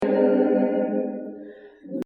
sur certaines fréquences on entend clairement une sorte de chorus ou flanging ! et les voix sont quand-même un peu dénaturées dans l'ensemble - en tout cas le fichier "choeur" (les voix toutes seule) contient pas mal d'artefacts et mauvaises résonances tout le long; et on l'entend très fortement sur les fins de phrase et à la respiration des chanteurs/euses (résonance genre boite de conserve)
et puis tu verras qu'une fois que tu as "repéré" les "colorations flanging" on les entend clairement tout le long de l'enregistrement... ces colorations sont dues aux déphasages
c'est sans doute dû à l'utilisation et emplacement de nombreux micros et leur différences de qualité (mais aussi dû en partie à la reverbe du lieu)